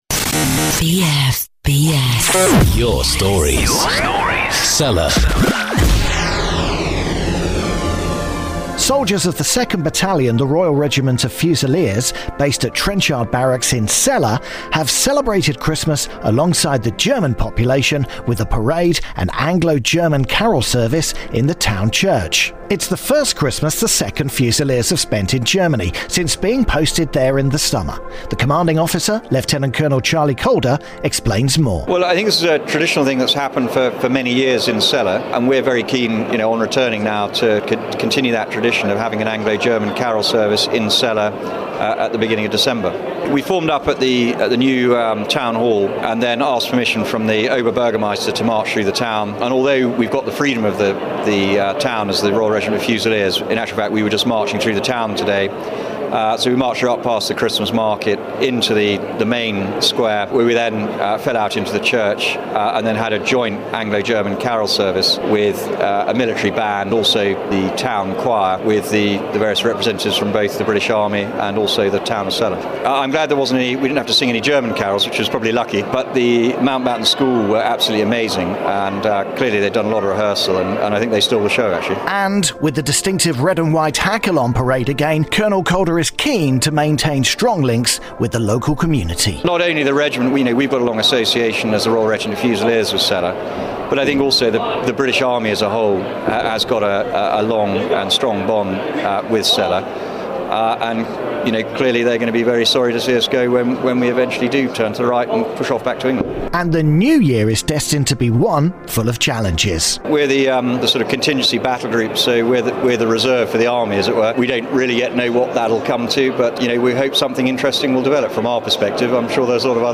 2RRF Carol Service